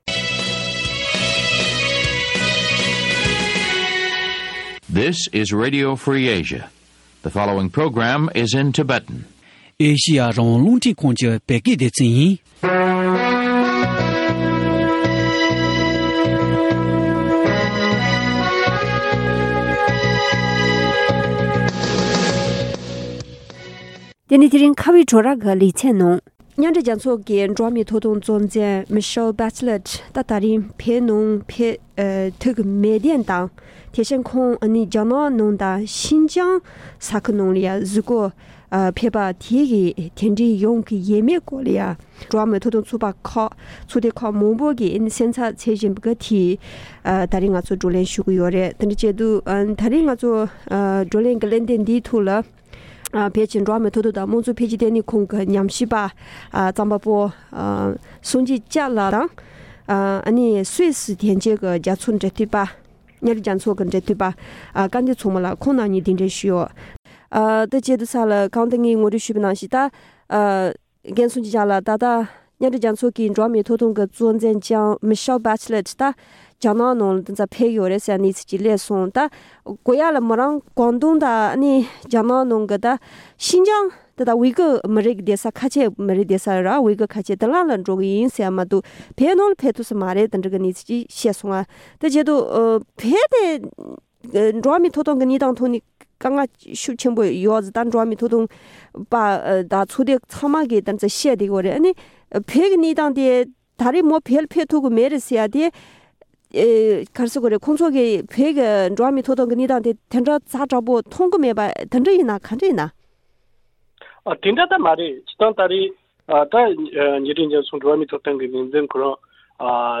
མཉམ་འབྲེལ་རྒྱལ་ཚོགས་ཀྱི་འགྲོ་བ་མིའི་ཐོབ་ཐང་ལྷན་ཚོགས་ཀྱི་གཙོ་འཛིན་བོད་ནང་ཕེབས་མ་ཐུབ་པའི་རྒྱུ་མཚན་ཐད་གླེང་མོལ་ཞུས་པ།